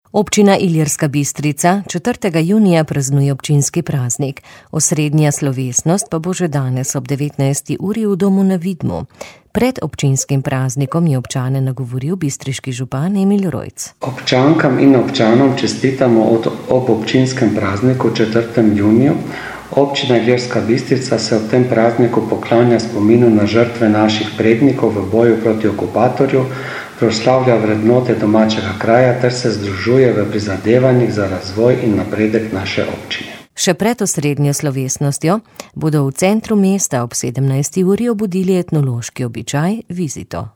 Pred občinskim praznikom  je občane nagovoril bistriški župan Emil Rojc.